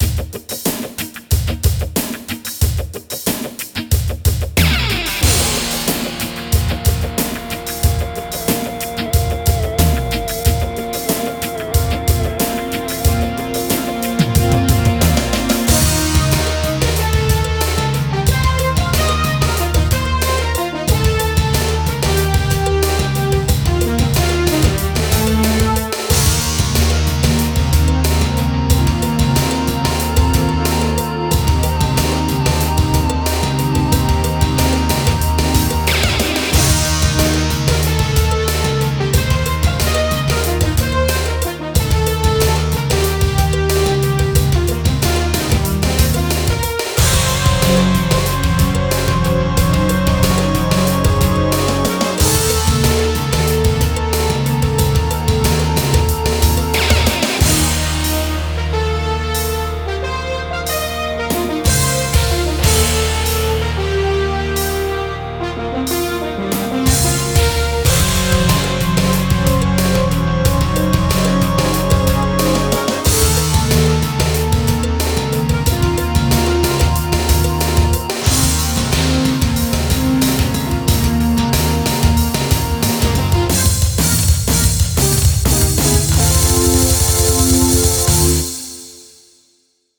• On-Board Demos